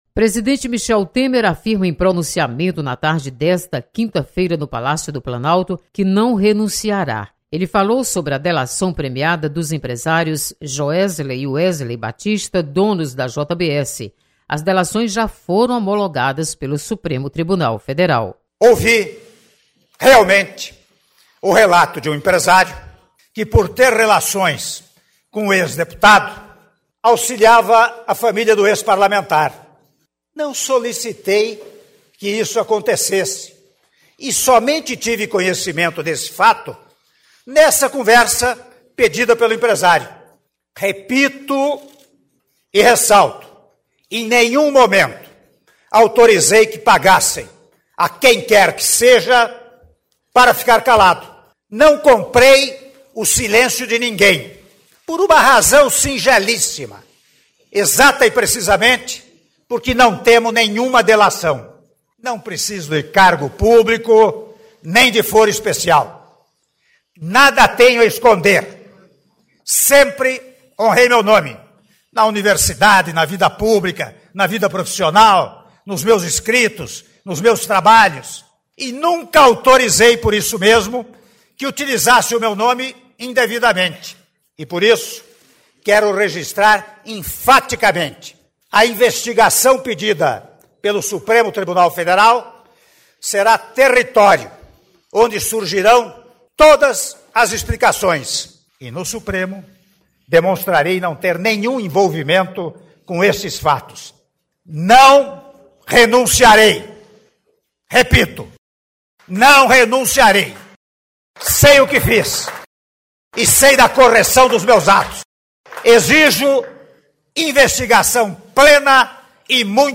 Deputados comentam pronunciamento de Michel Temer.